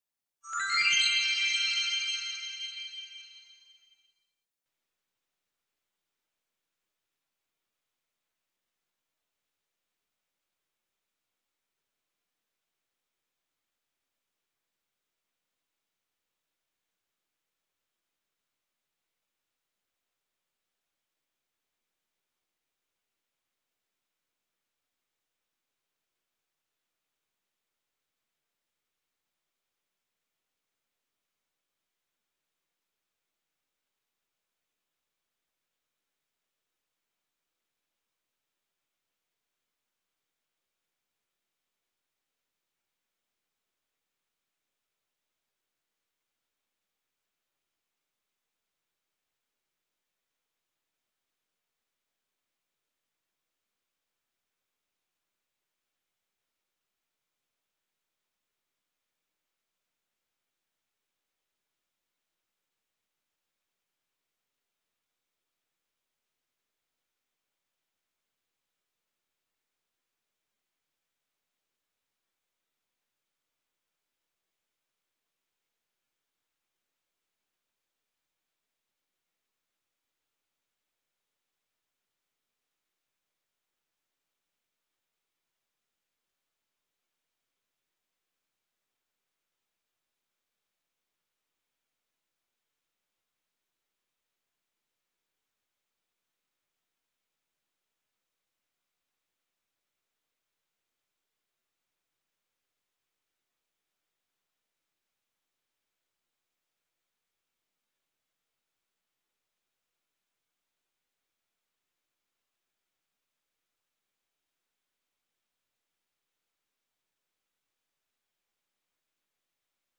Primera tutoría virtual del curso vibraciones y ondas.